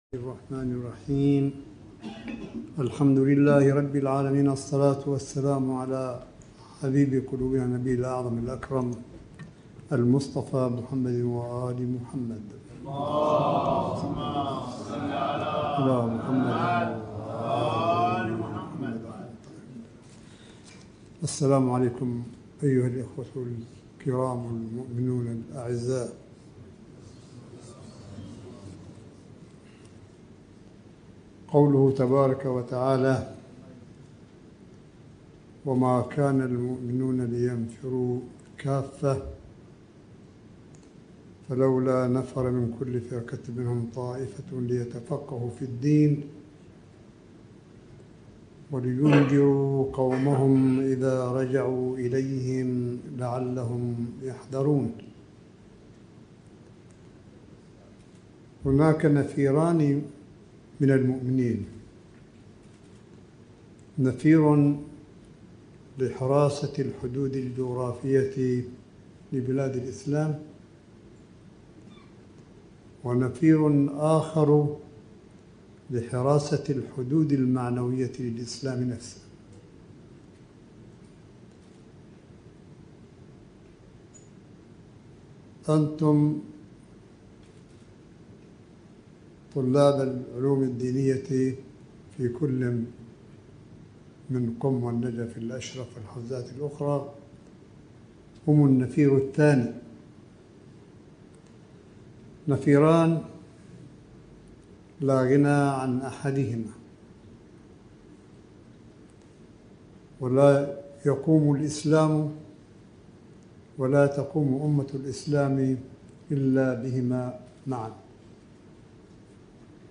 ملف صوتي : كلمة آية الله الشيخ عيسى أحمد قاسم في مراسم بداية العام الدراسي للحوزة العلميَّة بمدينة قم المقدسة – جامعة آل البيت العالميَّة ٣ صفر ١٤٤١هـ المُوافق ٢ اكتوبر ٢٠١٩م